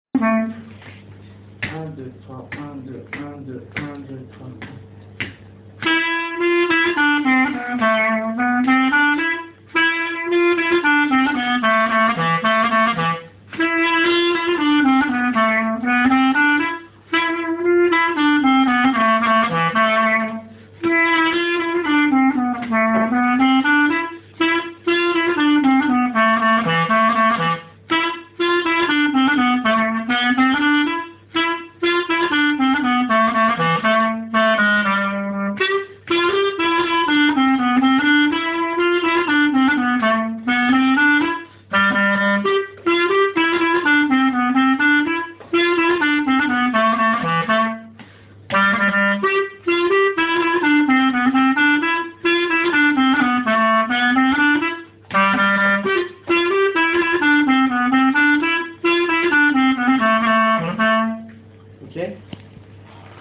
:mp3:2010:stages:clarinette